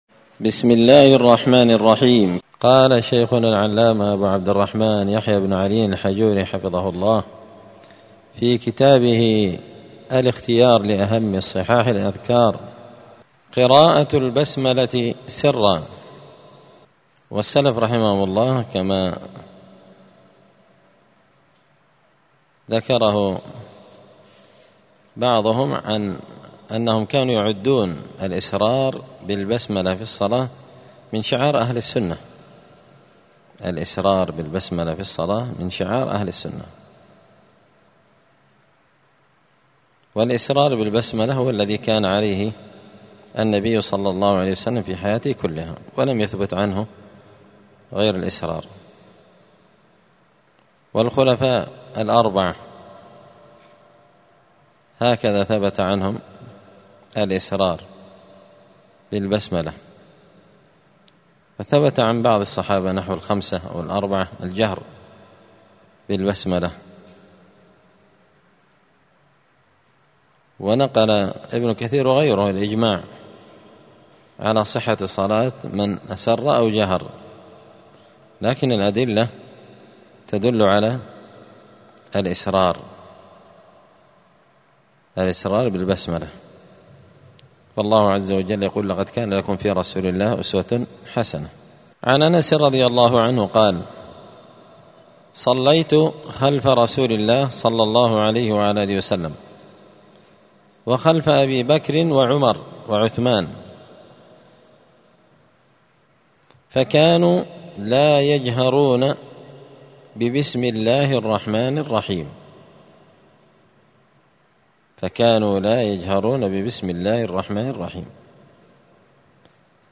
*{الدرس السابع والعشرون (27) أذكار الصلاة قراءة البسملة سرا}*